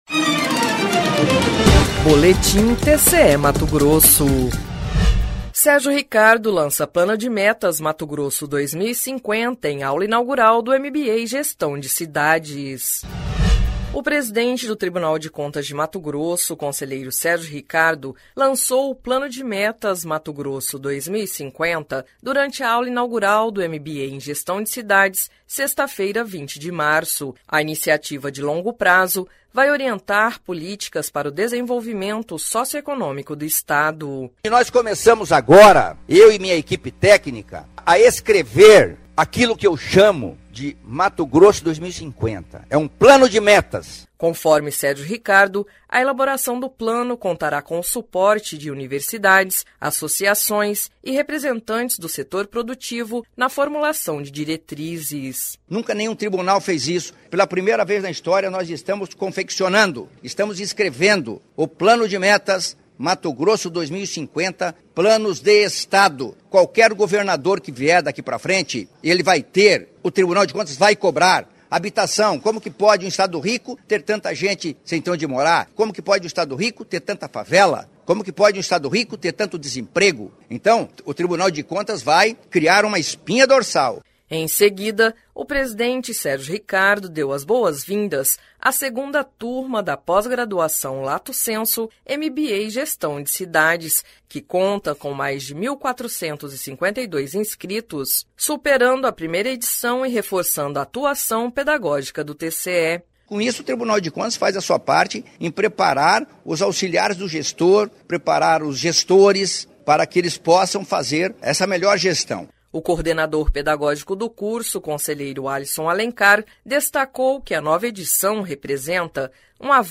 Sonora: Sérgio Ricardo – conselheiro-presidente do TCE-MT
Sonora: Alisson Alencar – conselheiro do TCE-MT